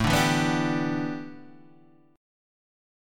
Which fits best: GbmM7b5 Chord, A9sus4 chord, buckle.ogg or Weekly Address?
A9sus4 chord